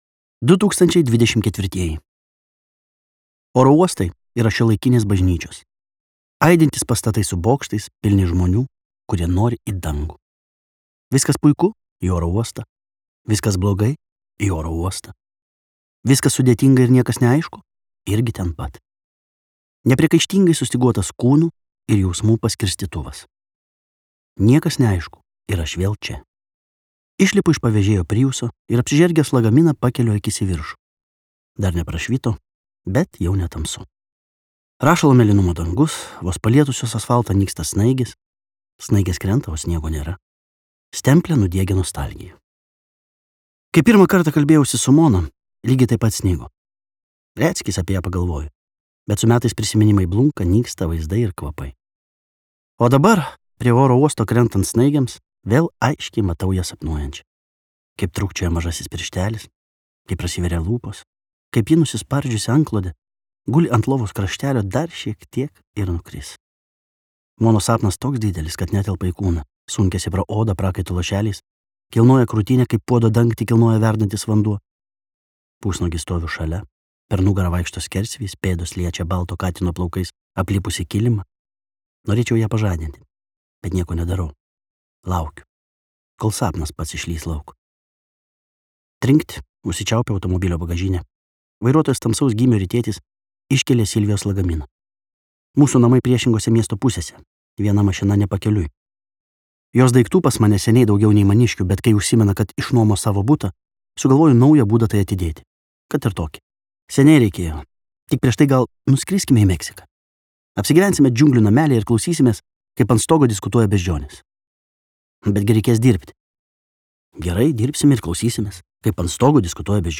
Jausmai | Audioknygos | baltos lankos